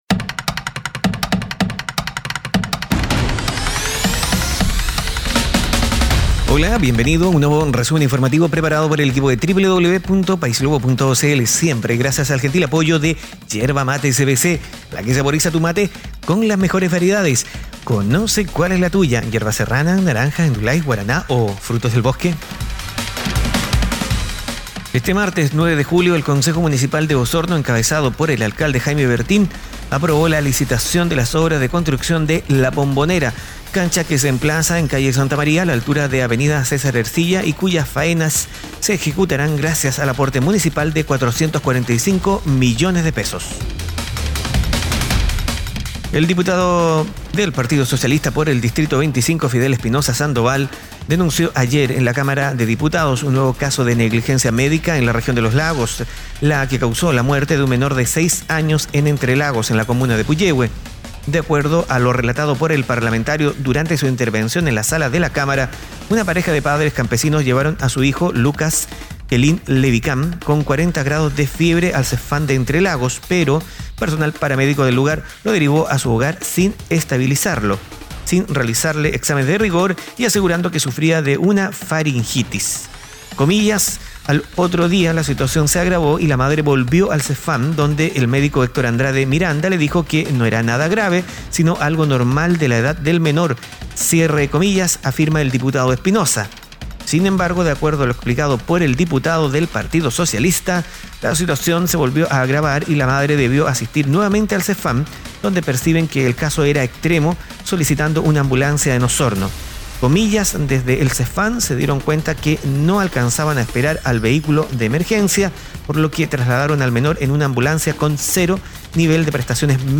Noticias e informaciones en pocos minutos.